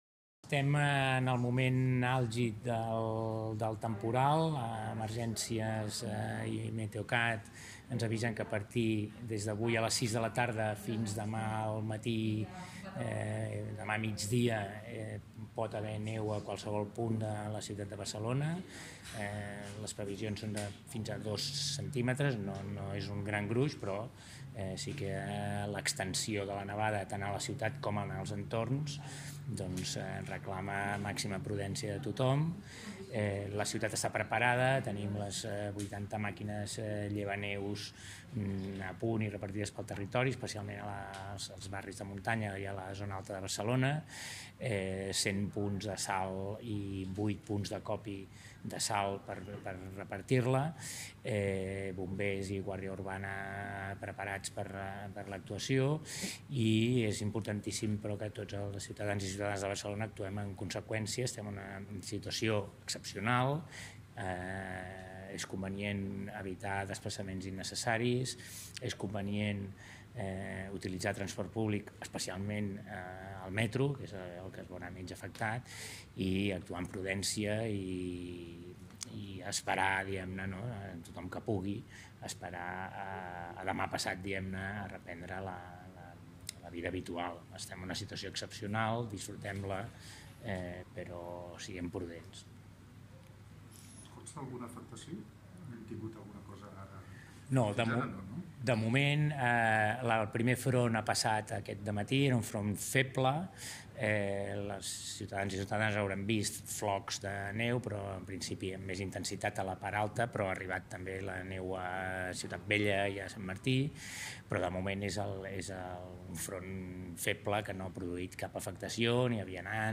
El comissionat d’Ecologia, Frederic Ximeno, assegura que la ciutat està preparada davant la previsió de nevades en les properes hores, i demana evitar desplaçaments innecessaris i utilitzar el transport públic, especialment el metro